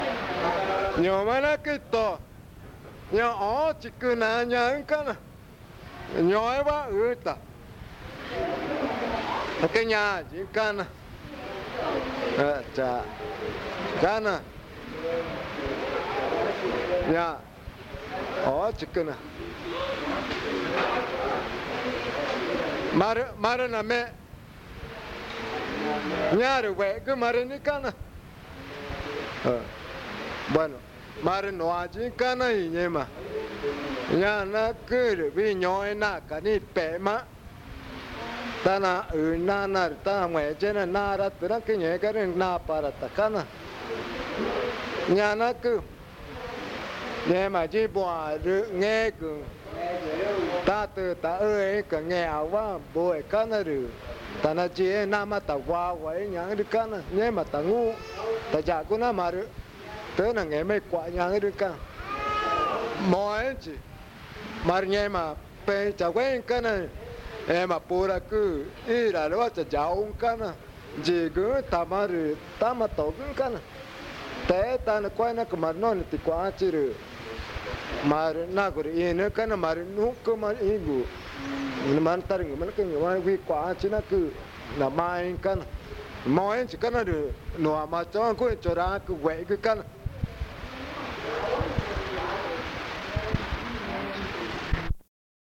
Tipisca, Amazonas (Colombia)